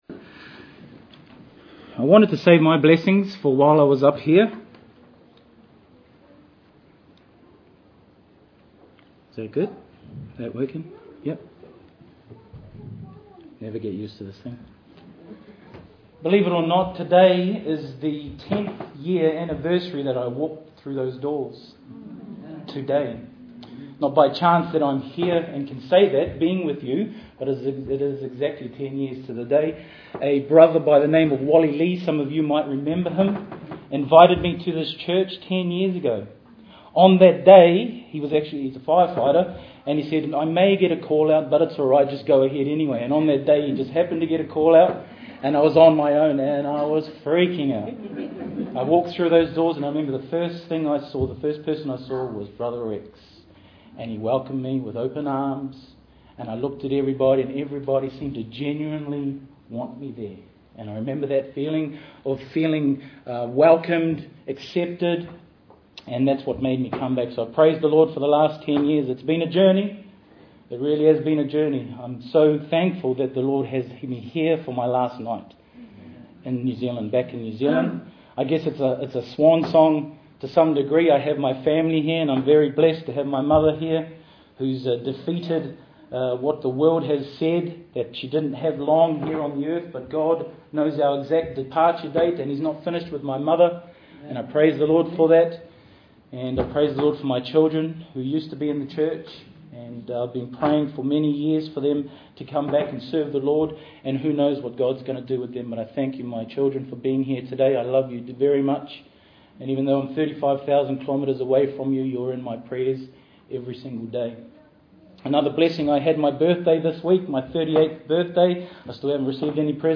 Have You Any Room for Jesus Guest Preachers , Wednesday Evening http